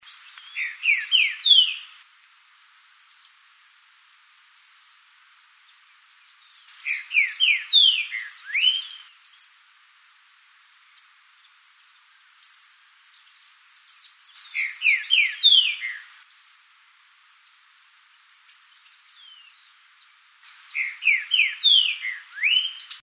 Green-winged Saltator (Saltator similis)
Life Stage: Adult
Location or protected area: Reserva Privada y Ecolodge Surucuá
Condition: Wild
Certainty: Recorded vocal